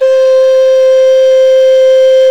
SULING C4.wav